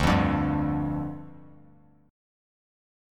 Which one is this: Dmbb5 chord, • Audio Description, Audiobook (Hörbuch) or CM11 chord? Dmbb5 chord